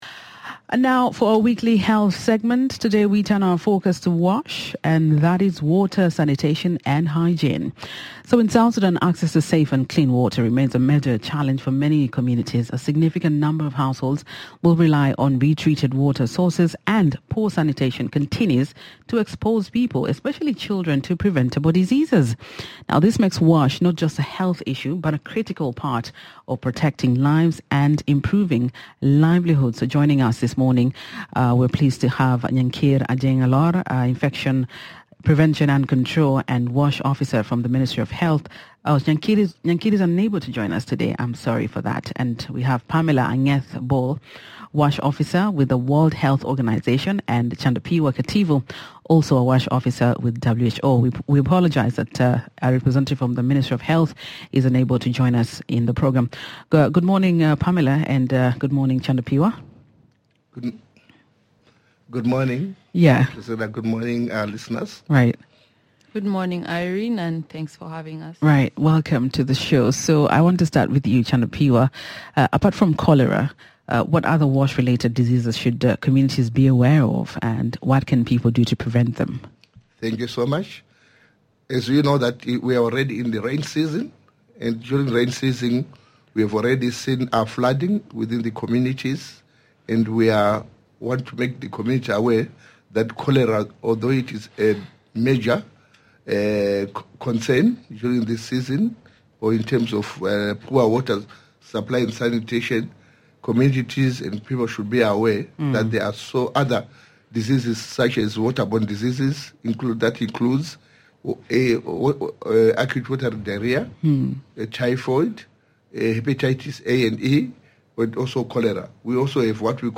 MBS's health segment focused on water, sanitation, and hygiene, highlighting the urgent need for safe, clean water in South Sudan, especially during the rainy season. The discussion explained that poor water and sanitation can lead to diseases such as cholera, typhoid, hepatitis, malaria, scabies and bilharzia, and stressed the importance of boiling, filtering or chlorinating water before drinking. Listeners also heard practical advice on safe storage, keeping water sources protected from animals and contamination, and the role of communities and water committees in managing water points.